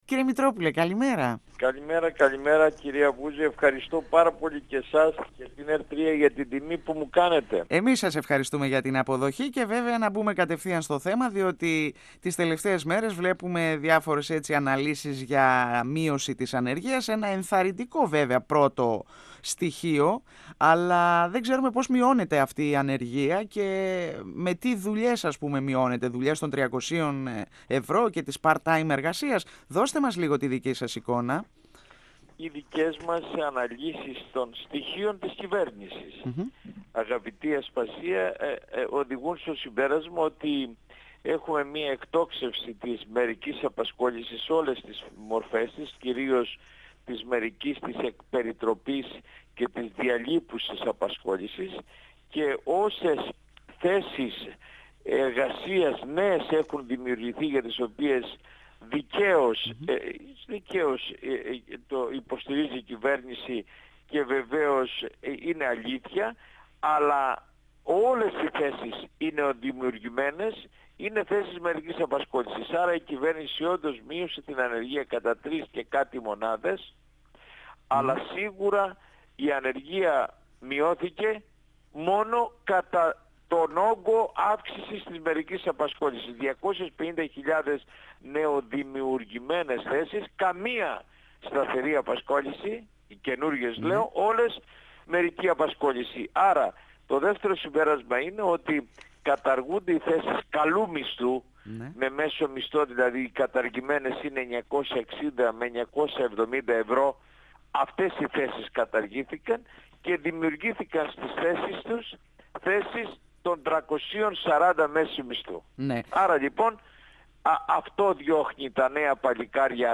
12Οκτ2017 – Ο εργατολόγος, πρόεδρος της Ένωσης για την Υπεράσπιση της Εργασίας και του Κοινωνικού Κράτους (ΕΝΥΠΕΚΚ) Αλέξης Μητρόπουλος στον 102 fm της ΕΡΤ3